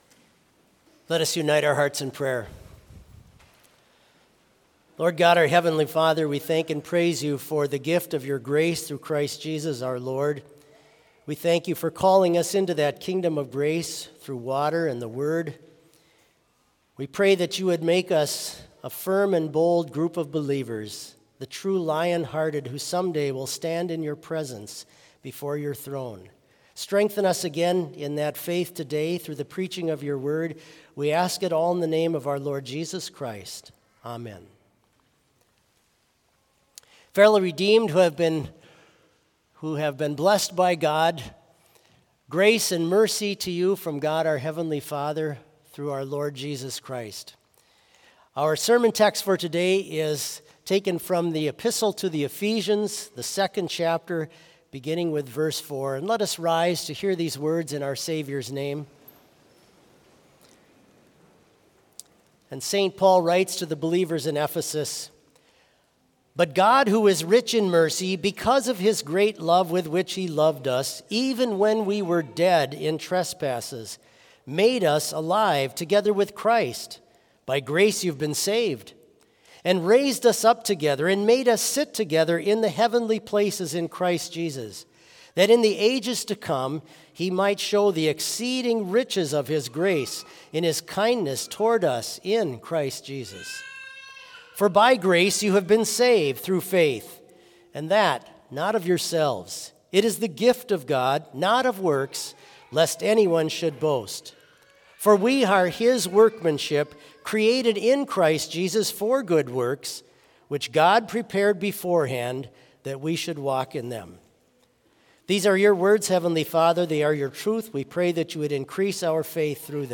Complete service audio for Fall Festival Service - Sunday, Sept 29, 2024